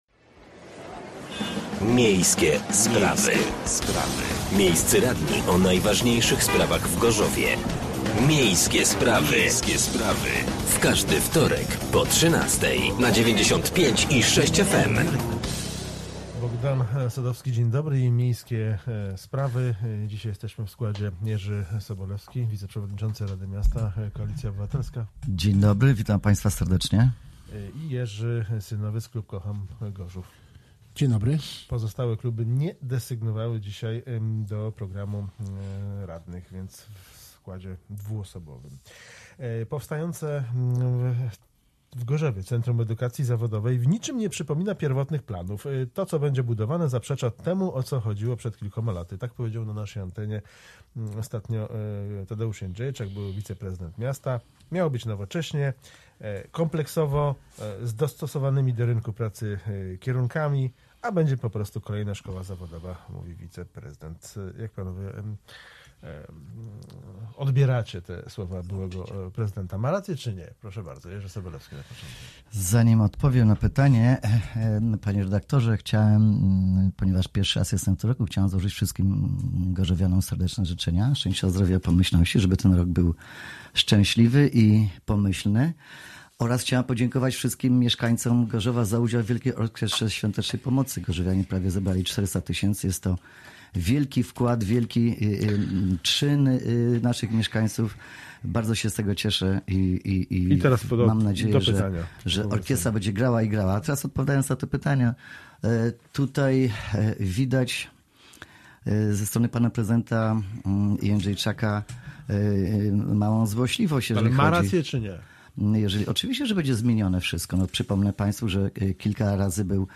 Gośćmi byli radni: Jerzy Synowiec (Kocham Gorzów) , Jerzy Sobolewski (Koalicja Obywatelska)